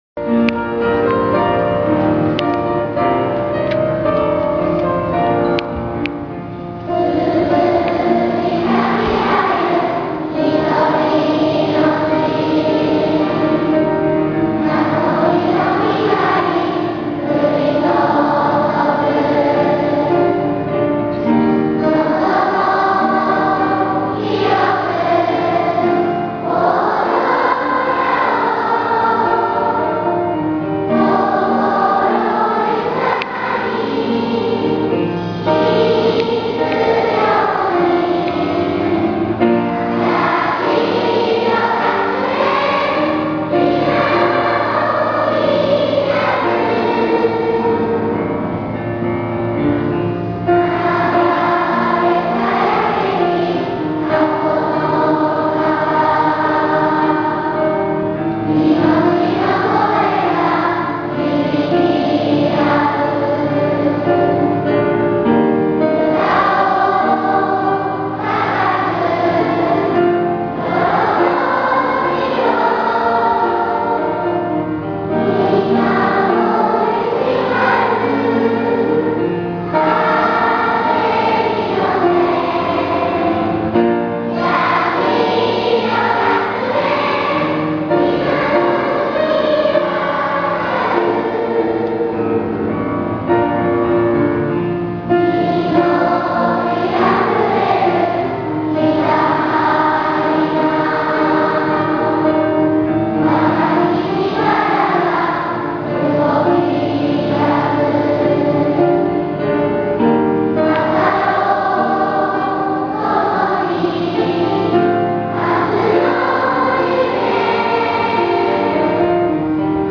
旋律はまず加東市の自然の美しさを、そしてその後に高く飛翔していきます。